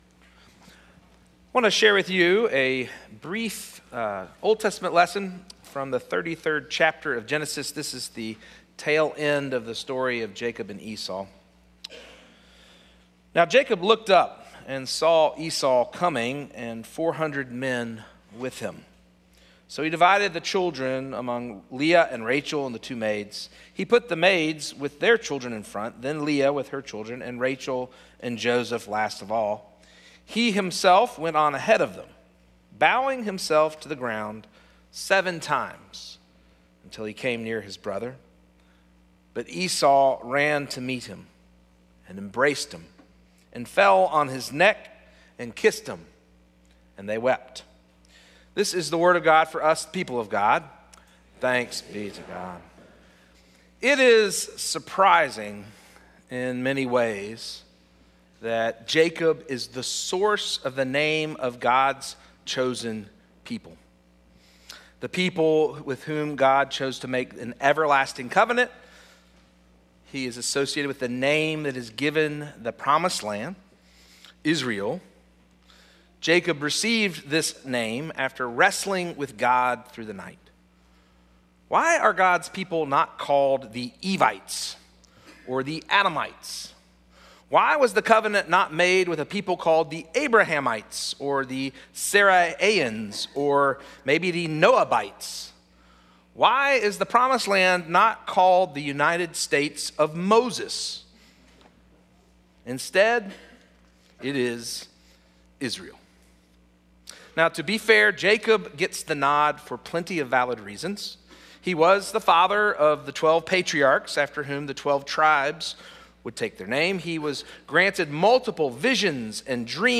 “humbled” Sermon Series, Week 3